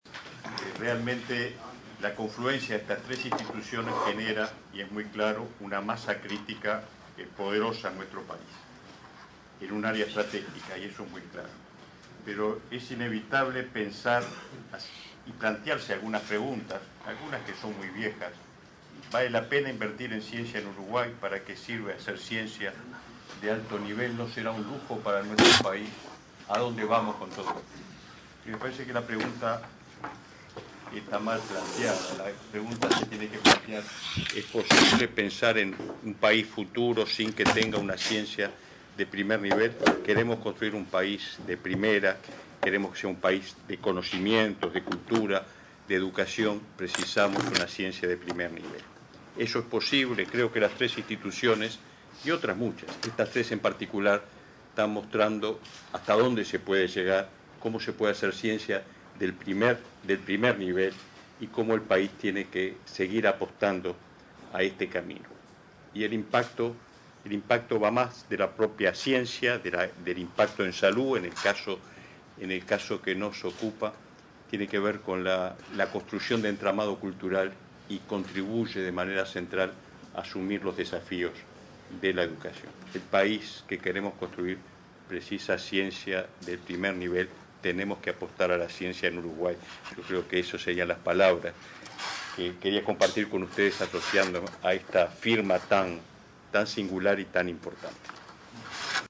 Palabras del ministro de Educación y Cultura, Ricardo Ehrlich, en la Firma del Acuerdo en CUDIM para la investigación de enfermedades del cerebro y diversos tipos de cáncer.